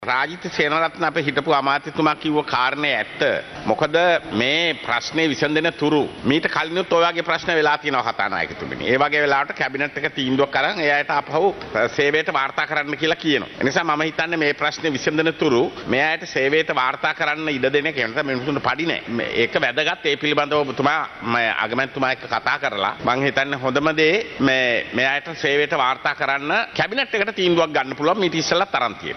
මේ පිළිබදව එස්. බී. දිසානායක මහතා ද අද පාර්ලිමේන්තුවේදී මෙලෙස අදහස් දක්වා සිටියා.